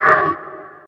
48d440e14c Divergent / mods / Soundscape Overhaul / gamedata / sounds / monsters / poltergeist / hit_0.ogg 17 KiB (Stored with Git LFS) Raw History